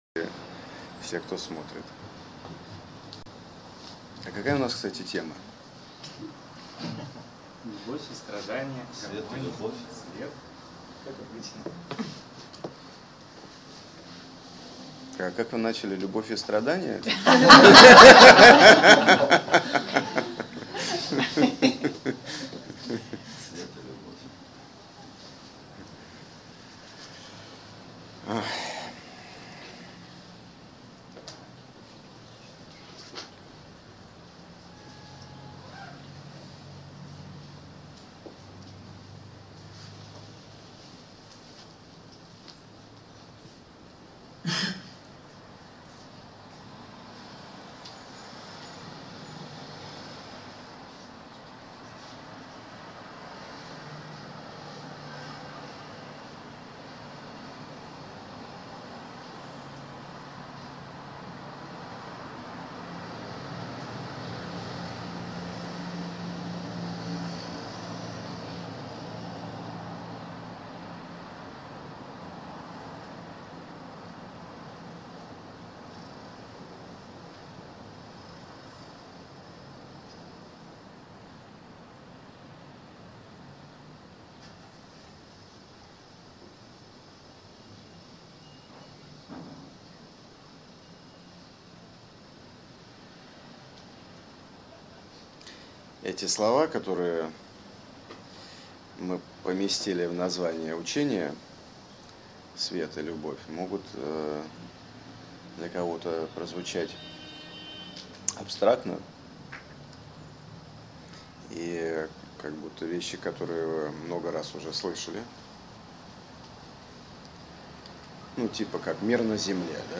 Аудиокнига Свет и Любовь — как практика для скорейшего духовного прогресса | Библиотека аудиокниг